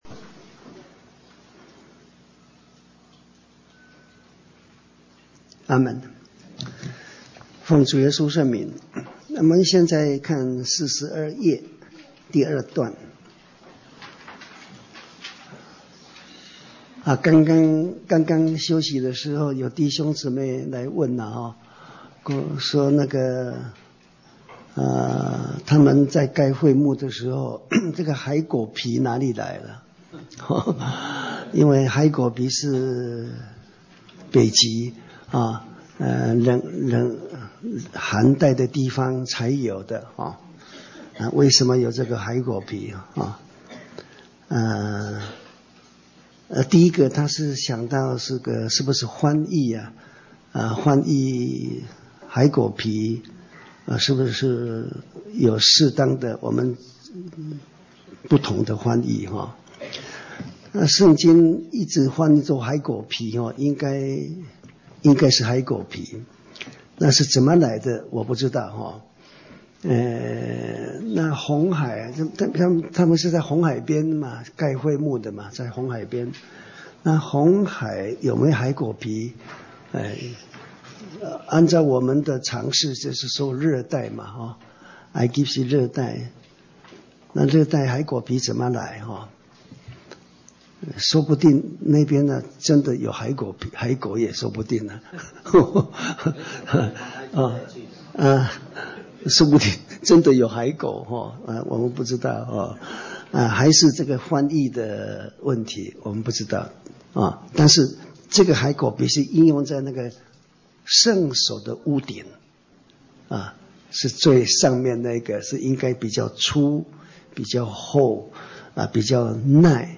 講習會
地點 台灣總會 日期 02/17/2014 檔案下載 列印本頁 分享好友 意見反應 Series more » • 出埃及記 22-1 • 出埃及記 22-2 • 出埃及記 22-3 …